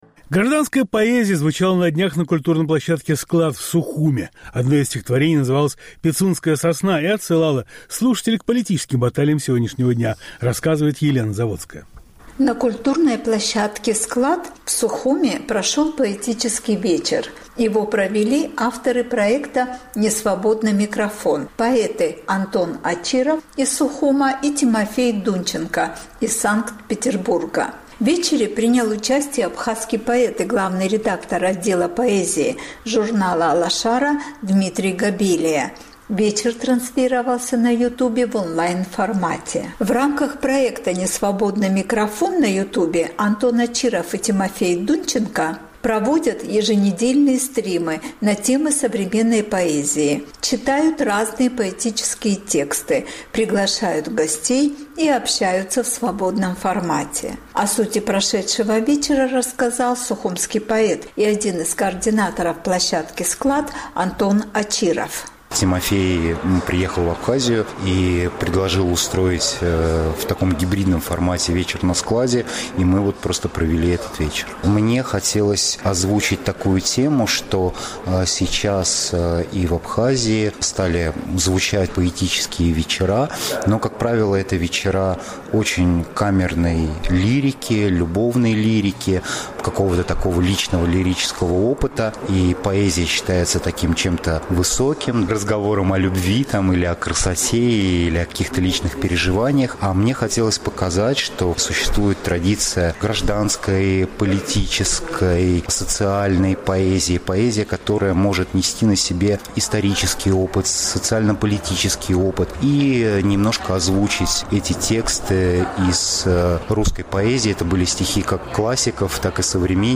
Стих Владимира Анкваба «Пицундская сосна» прочли на поэтическом вечере
Гражданская, социальная и политическая поэзия звучала на культурной площадке «Склад» в Сухуме.